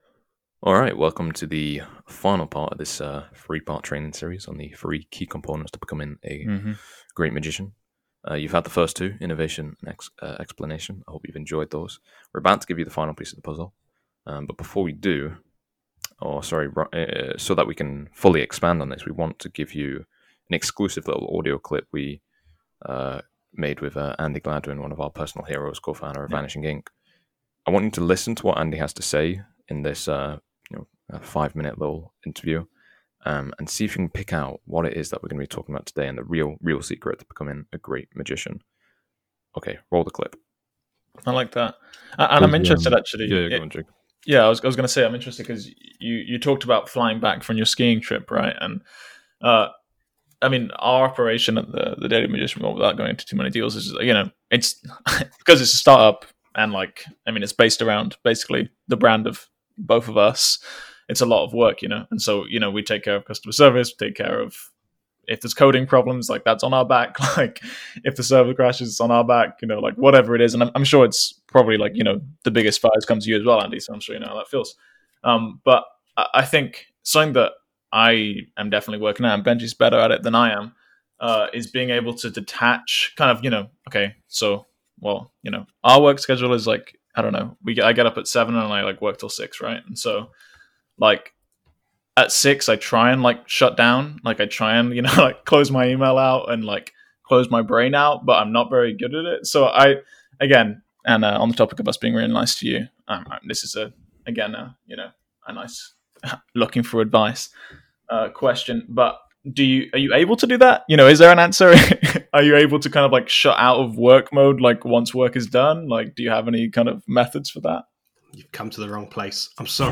This material didn’t air—and nobody’s ever heard it. But we decided to release it today, because it’s a perfect example of the third secret to becoming a great magician.